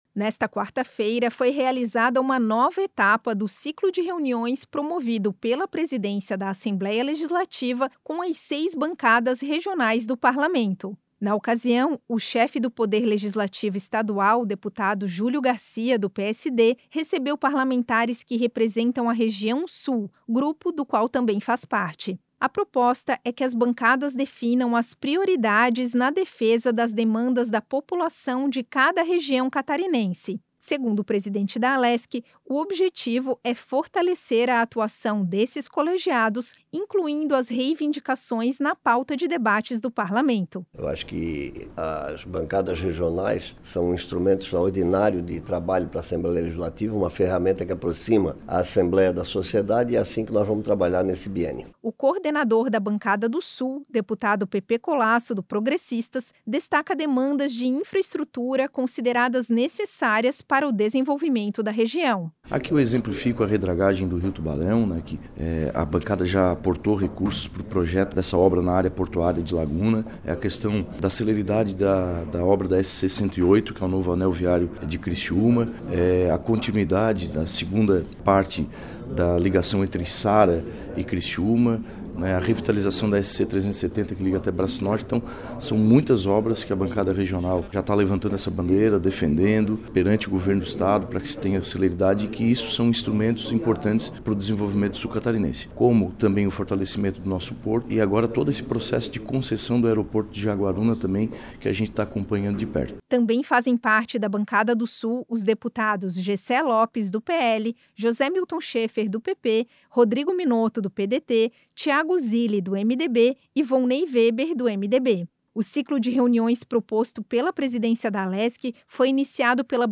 Entrevistas com:
- deputado Julio Garcia (PSD), presidente da Assembleia Legislativa;
- deputado Pepê Collaço (PP), coordenador da Bancada do Sul da Assembleia Legislativa.